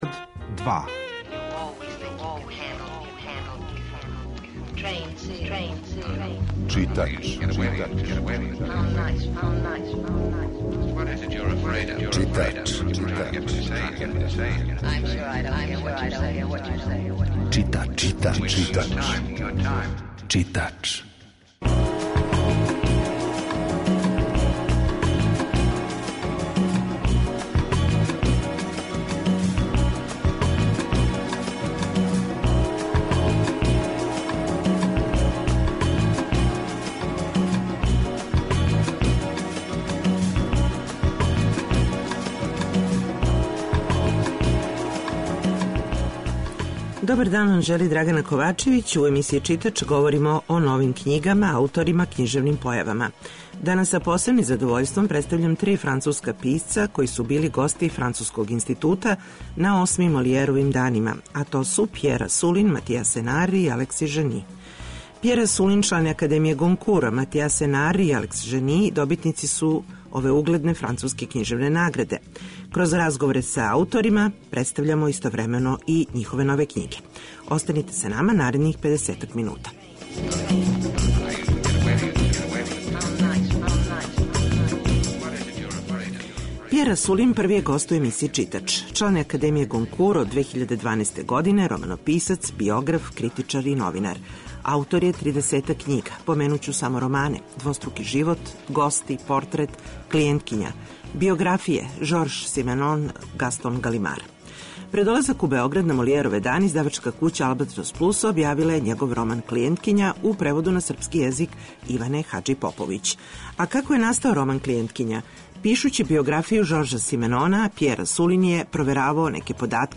Чућете три разговора са три госта 'Молијерових дана', који су завршени крајем прошле недеље. То су књижевници из Француске: Пјер Асулин, Матијас Енар и Алекси Жени.